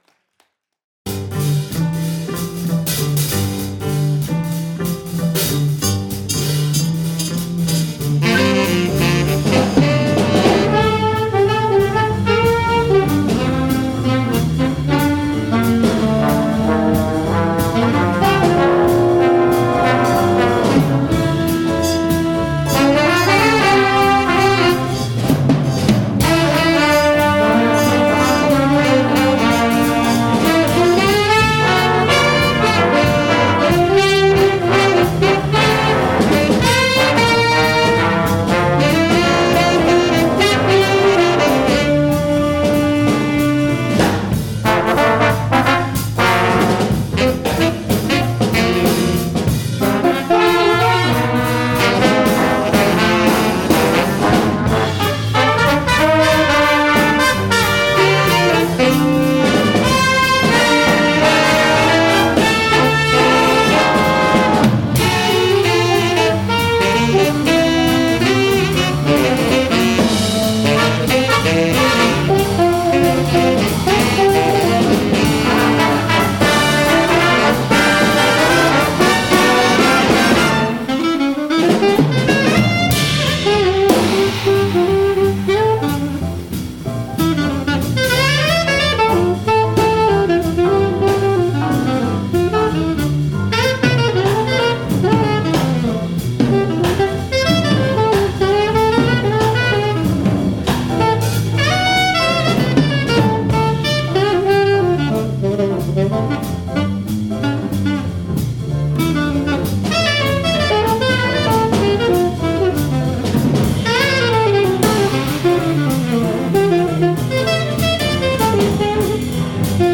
TEMPLE BIG BAND
Live Z-salen!